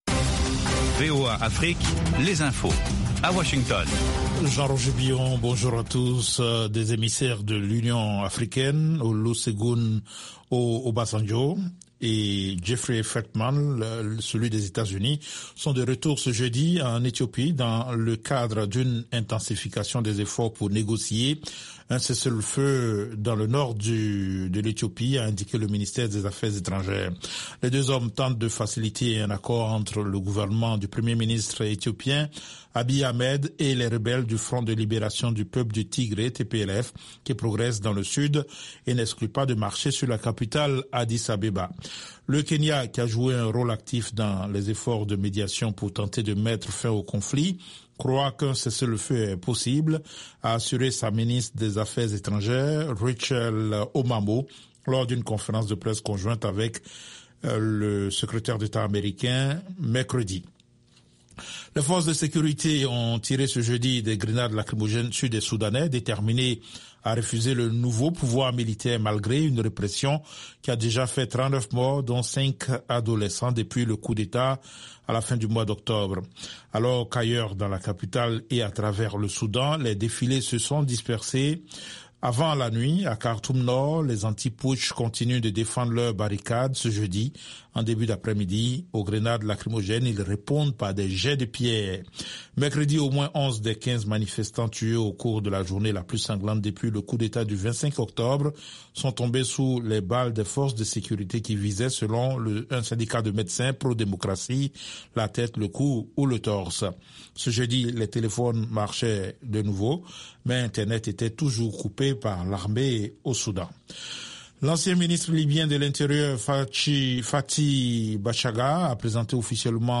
Bulletin
5 Minute Newscast